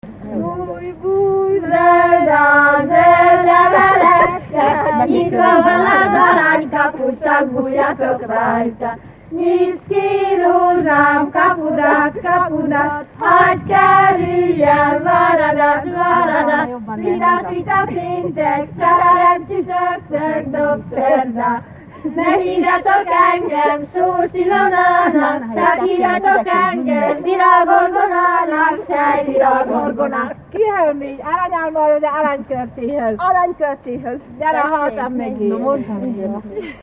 Alföld - Pest-Pilis-Solt-Kiskun vm. - Hévízgyörk
ének
Műfaj: Gyermekjáték
Stílus: 7. Régies kisambitusú dallamok